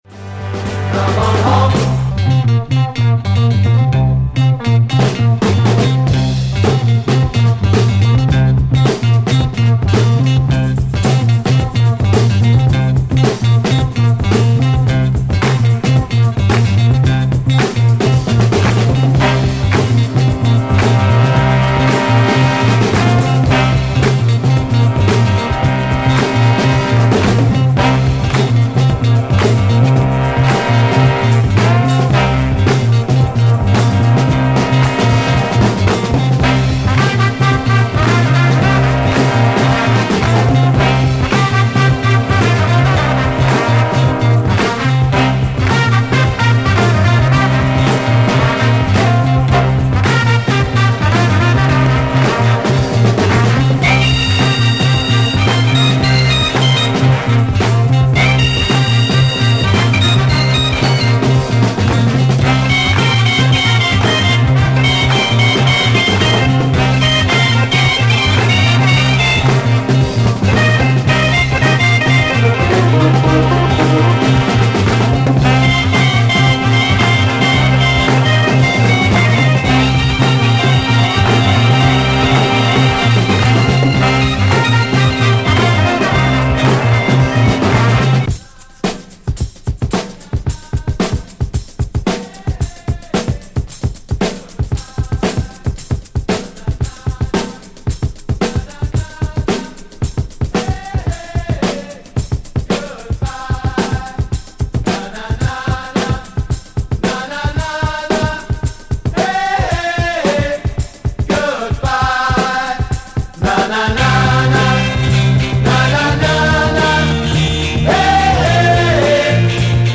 Soft Rock germany